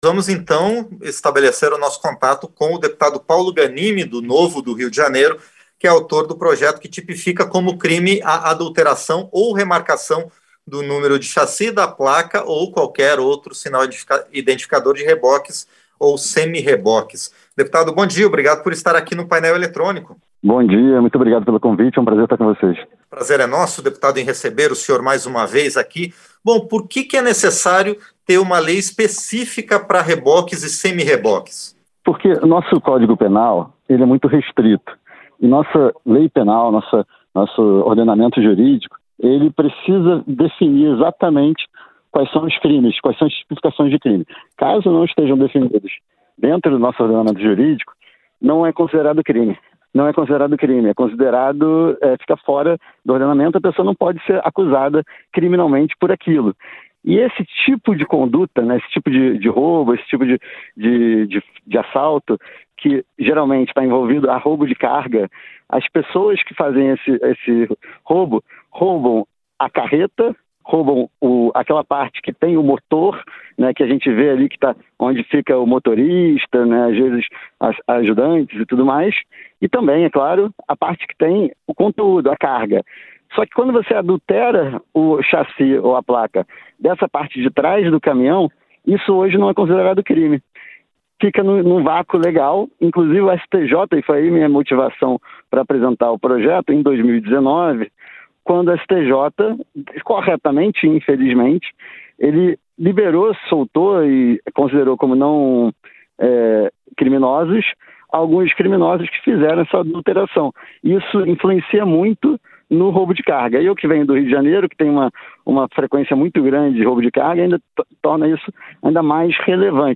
• Entrevista - Dep. Paulo Ganime (NOVO-RJ)